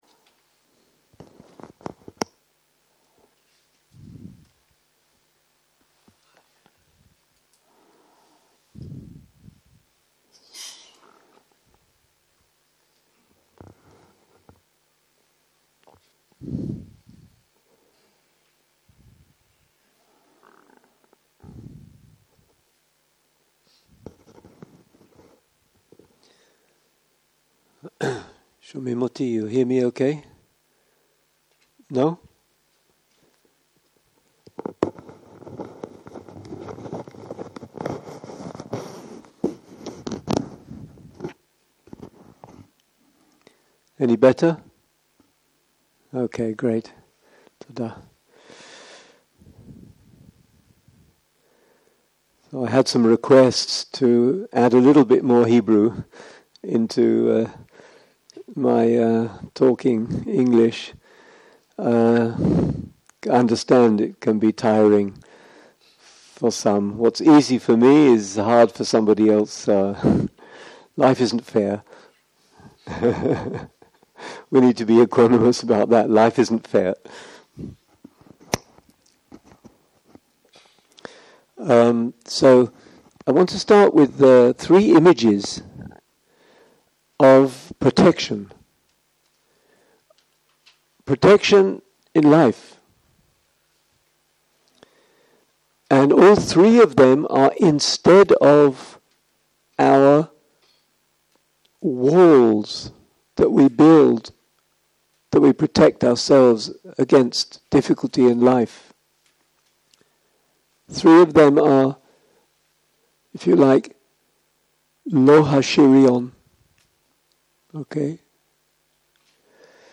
יום 3 - ערב - שיחת דהרמה - Allowing Things & Changing Them - הקלטה 7
סוג ההקלטה: שיחות דהרמה